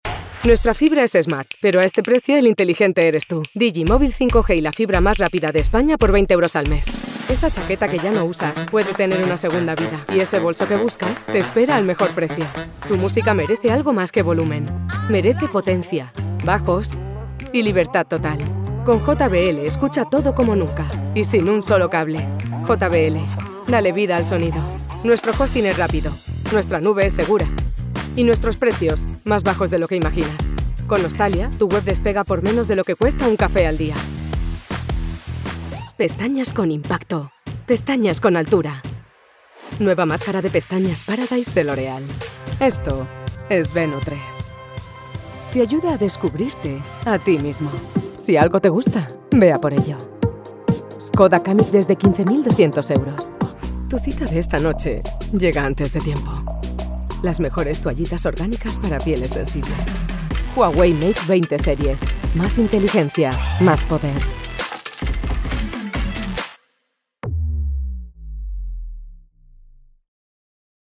European Spanish female voice over talent at your disposal!
Young Warm Friendly